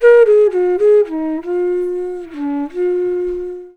FLUTE-B11 -R.wav